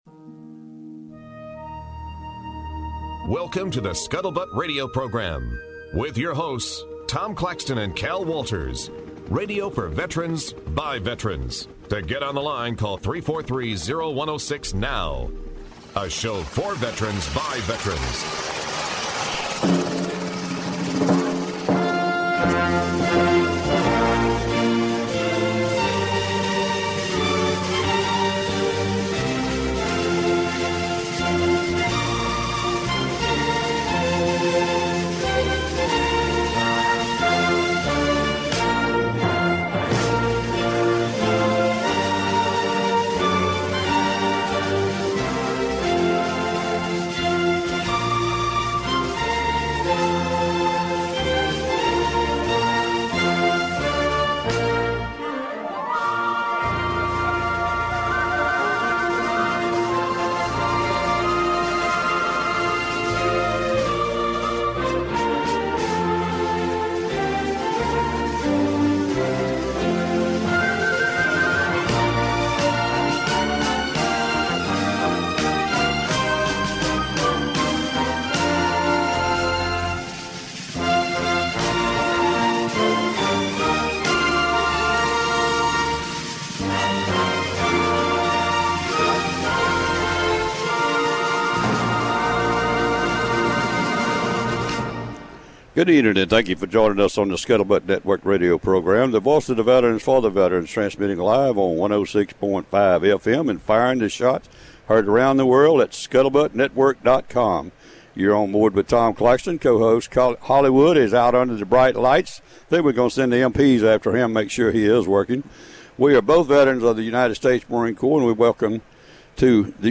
Today's show originates aboard the USS Alabama Battleship at Memorial Park in Mobile.